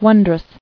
[won·drous]